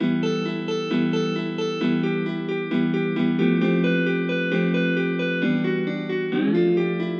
简单的吉他独奏
描述：一个有和弦分层的简易琶音。
Tag: 133 bpm Trap Loops Guitar Acoustic Loops 1.21 MB wav Key : E